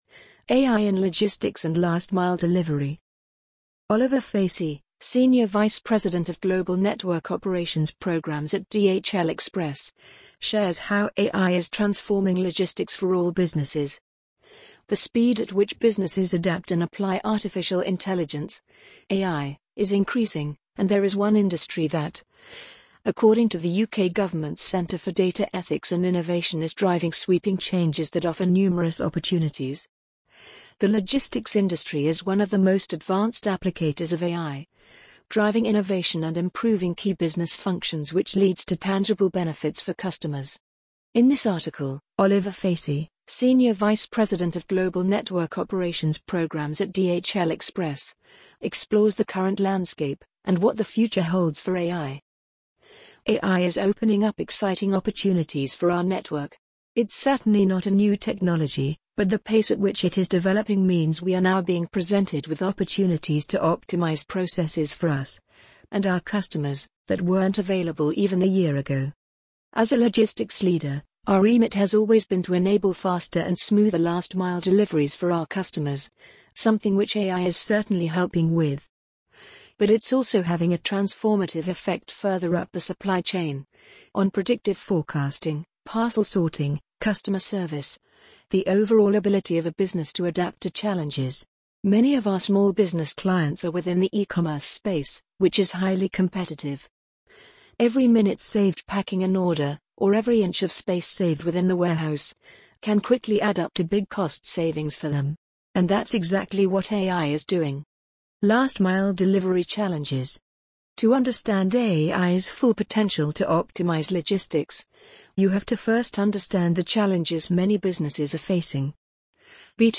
amazon_polly_47726.mp3